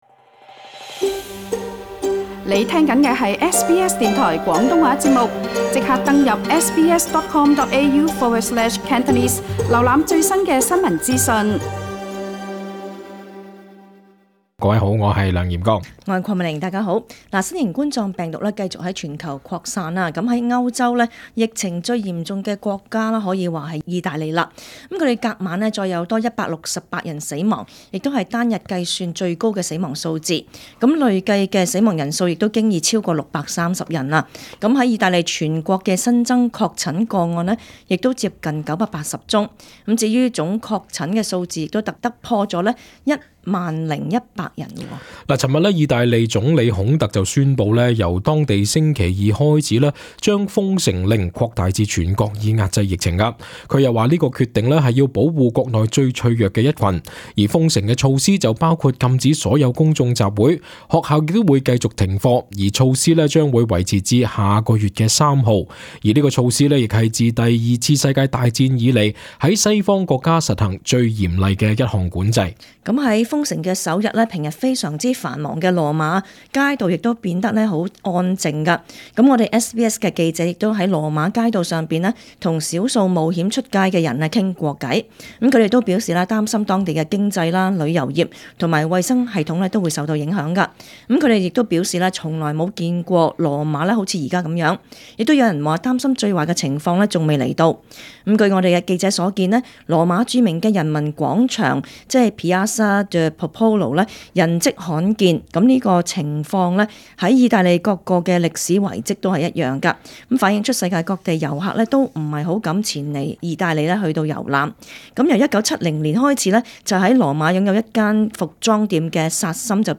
本台記者亦在羅馬街道上與少數冒險外出的人士傾談。